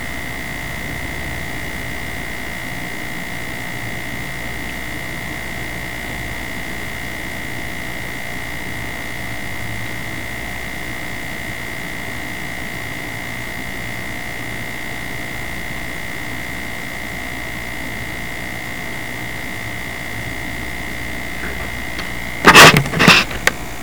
Â All your appliances at home including your lights anf powerline have some sort of buzz or hum to it.
Here is a jack and jill buzz.